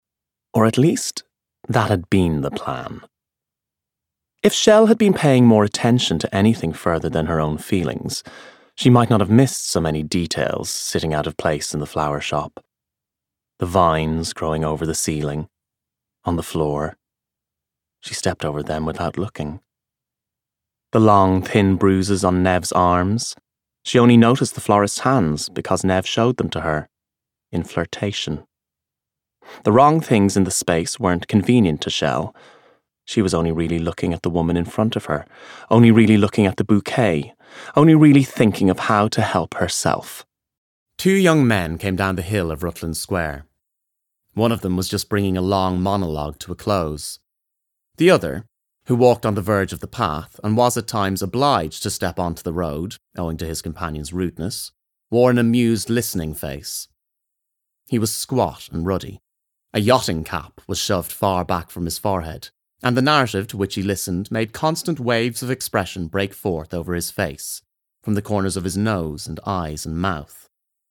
20s-30s. Male. Studio. Irish.
Audiobook